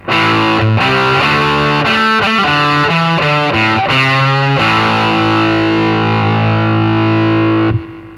Записано все на советский конденсаторный микрофон (или в линию).
комбик с эквалайзером